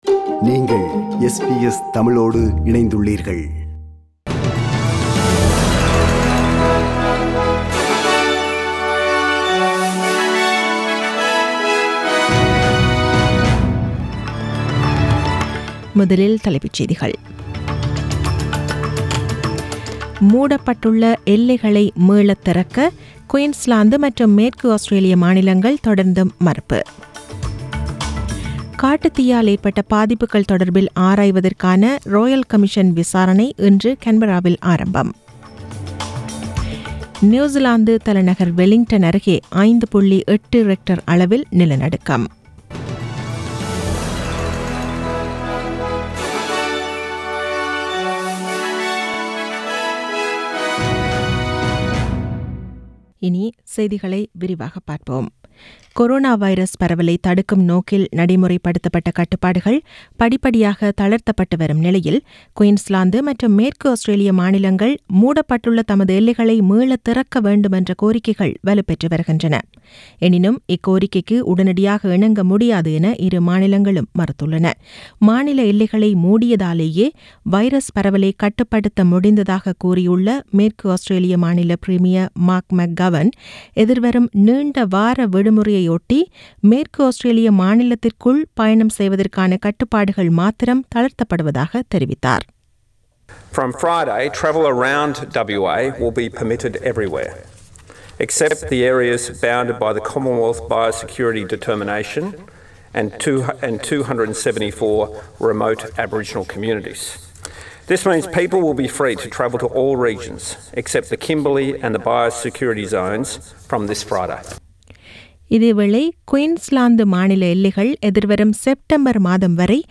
The news bulletin was aired on 25 May 2020 (Monday) at 8pm.